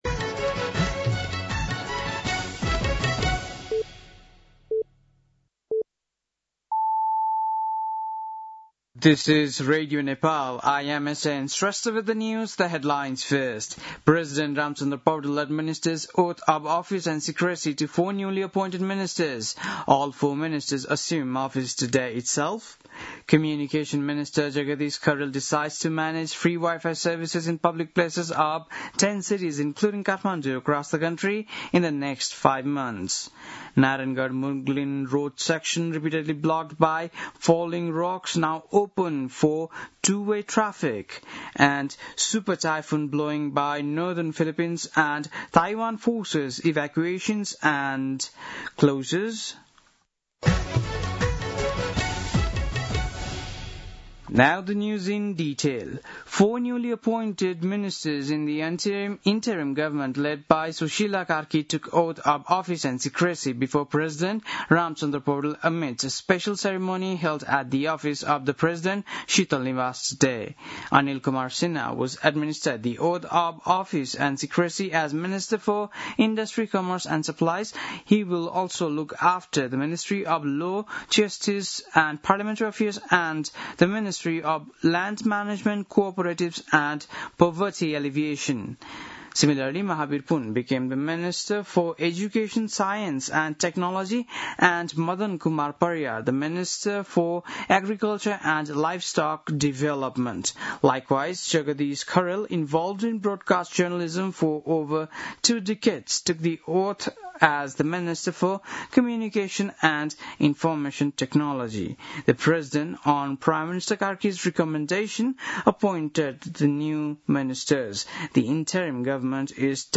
बेलुकी ८ बजेको अङ्ग्रेजी समाचार : ६ असोज , २०८२
8-pm-english-news-6-06.mp3